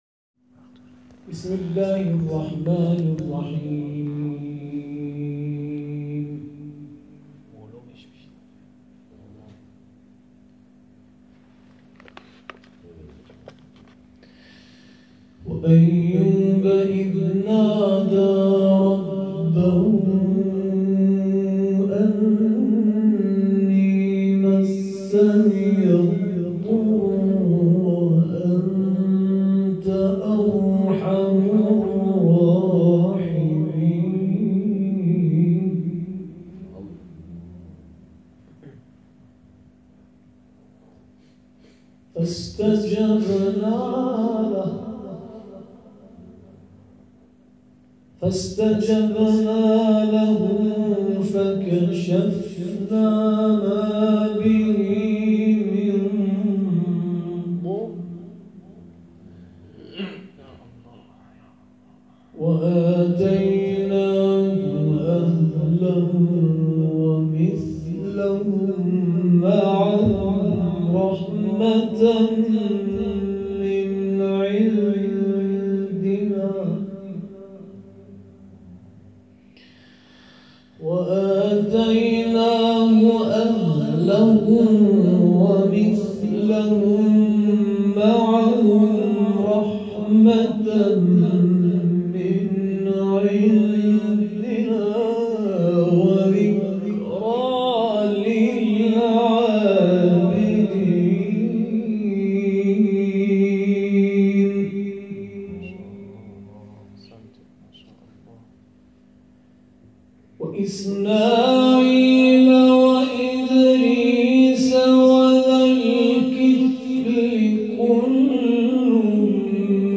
گروه مؤسسات قرآنی مردم‌نهاد ـ سی‌ و یکمین جلسه آموزش حفظ، قرائت و مفاهیم به همت مؤسسه کریمه آل رسول(س) برگزار شد.